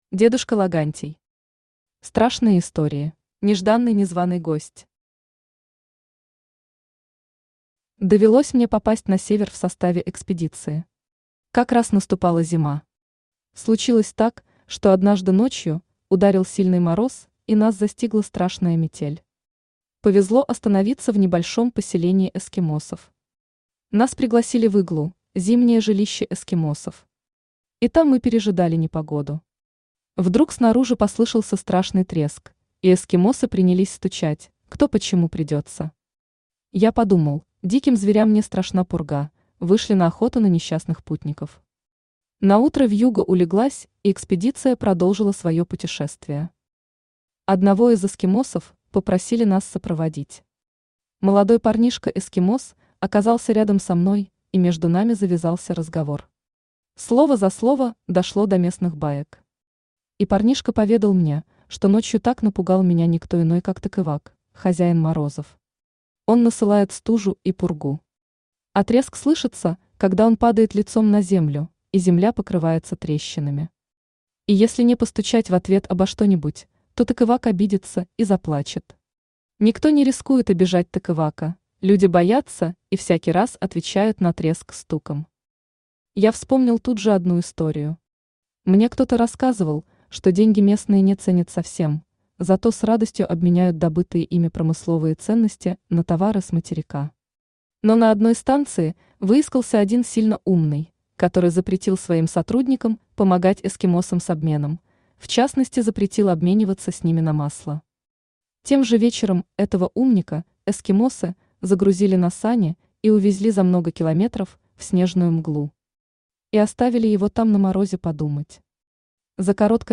Aудиокнига Страшные истории Автор дедушка Логантий Читает аудиокнигу Авточтец ЛитРес.